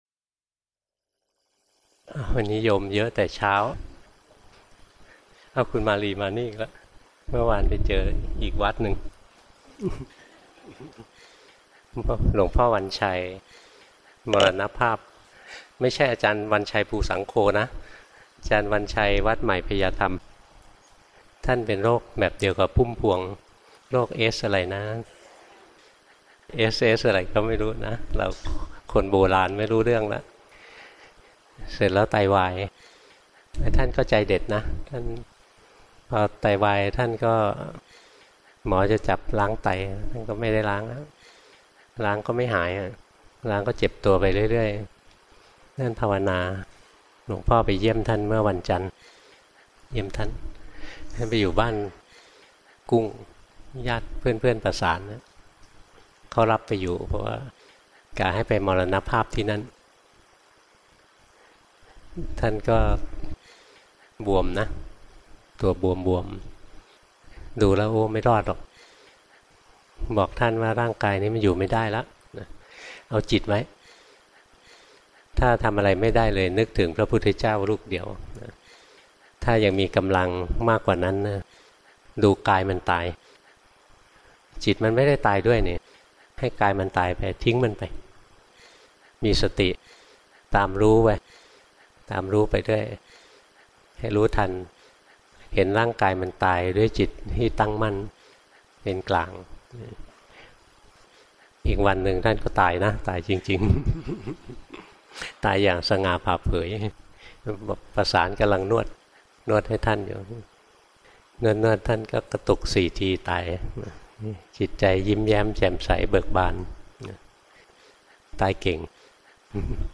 | in พุทธศาสนา ไฟล์เสียง พระอาจารย์ปราโมทย์ ปราโมชโช สวนสันติธรรม จ.ชลบุรี 29:37 minutes (13.56 MB) » Download audio file 242 downloads 11 plays